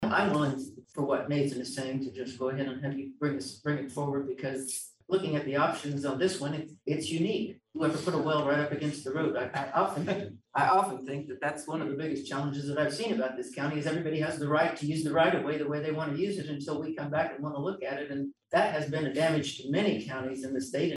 Commissioner Dee McKee spoke about how she’d like Planning and Zoning to proceed with plans.